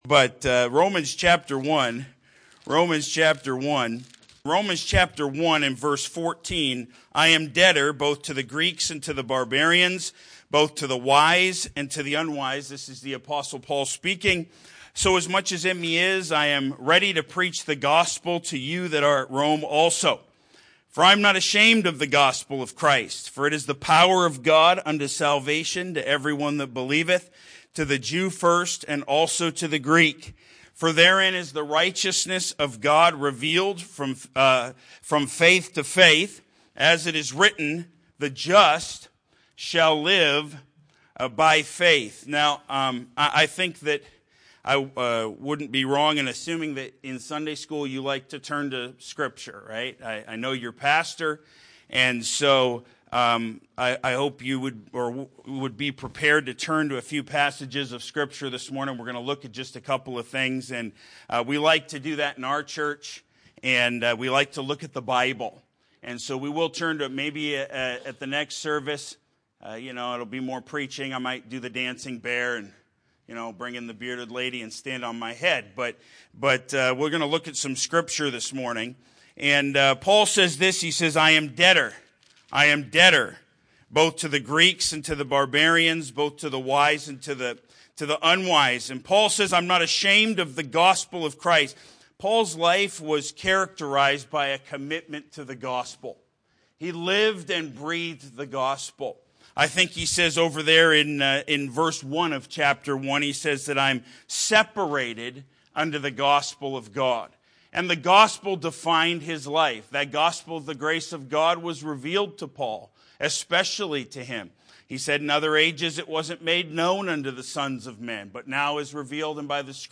As a missions message, we, like Paul, should see ourselves as debtors to the gospel, Rom 1:14, both for our own salvation and for the salvation of others to whom we must preach, throughout the world.